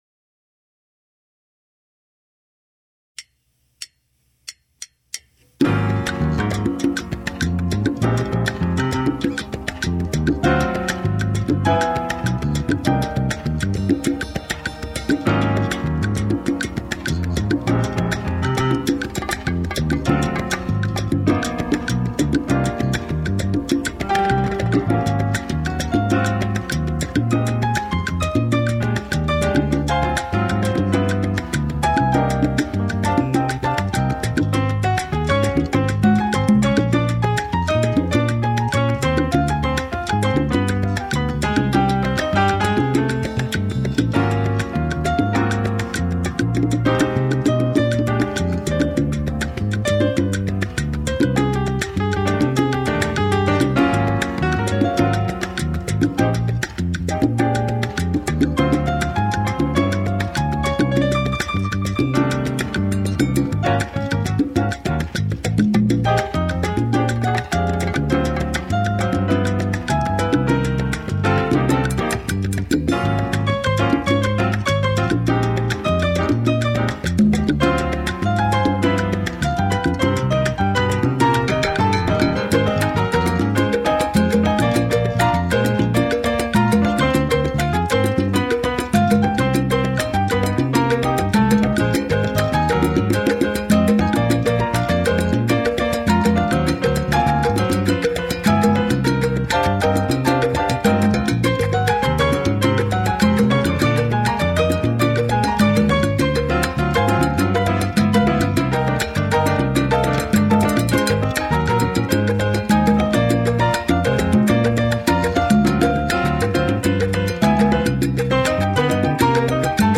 Voicing: Bass